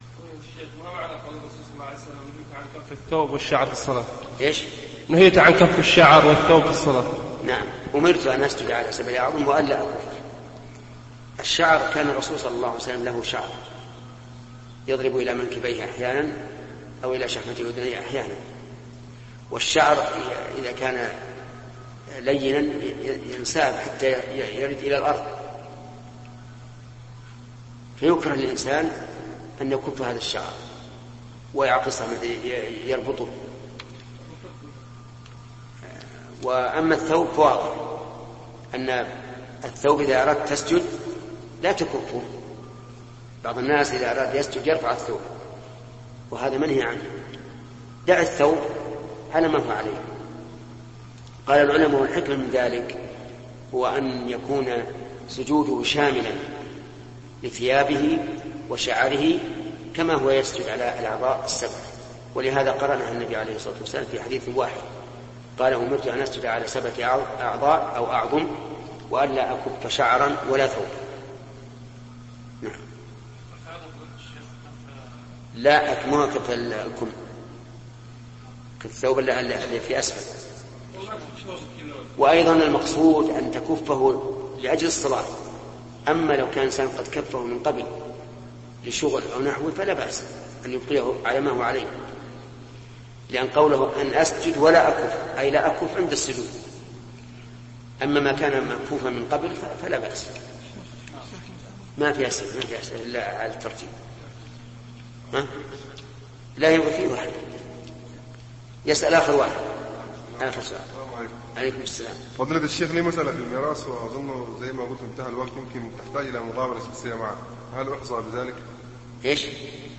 💫 الشيخ محمد بن صالح العثيمين